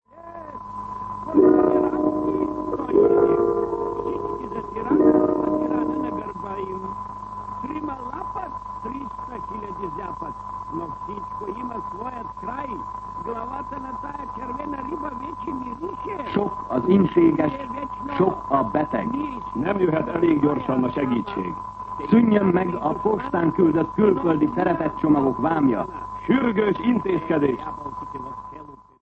Jelmondatok 1956. november 01. 21:49 ● 00:28 ► Meghallgatom Műsor letöltése MP3 00:00 00:00 A műsor leirata Szünetjel Sok az ínséges, sok a beteg.